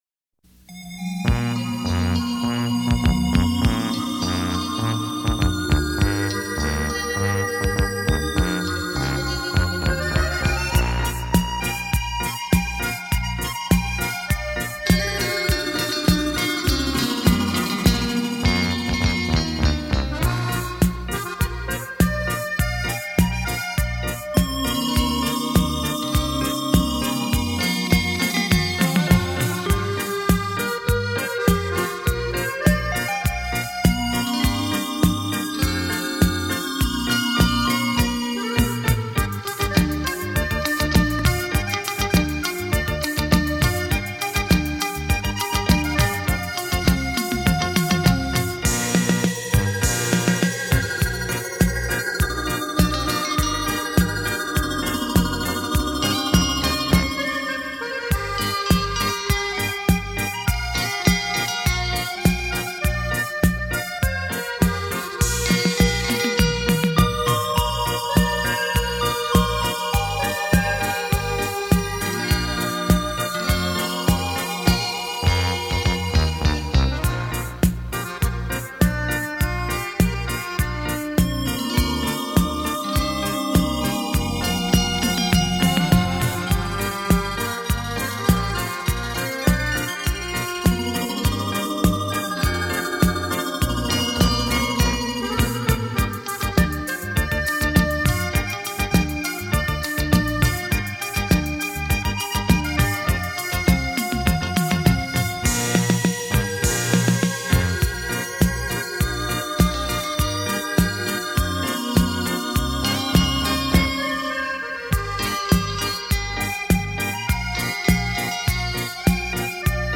超级立体音场环绕
沉浸在这感性的旋律里